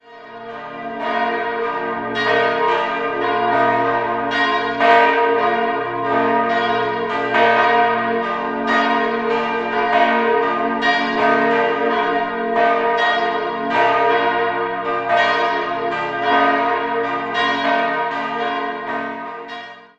Sie besitzt im Inneren eine bemerkenswerte Ausstattung im so genannten "Bauernbarockstil". 3-stimmiges B-Dur-Geläute: b°-d'-f' Die Glocken wurden im Jahr 1899 von Christoph Albert Bierling in Dresden gegossen.